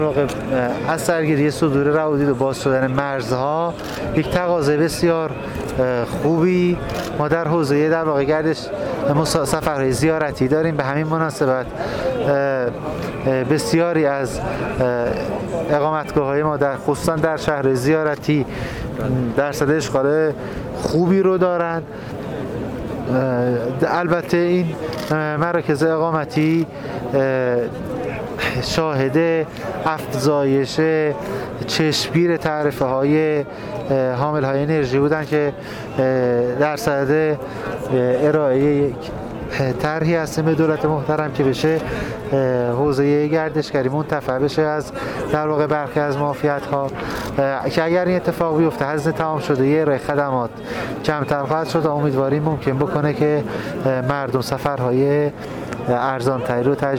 شالبافیان در گفت‌وگو با ایکنا تببین کرد؛